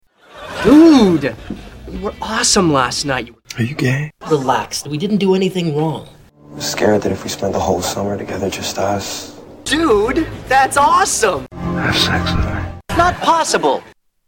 Tags: Soundboards Talk to each other Mash up Mash up clips Mash up sounds Soundboard talking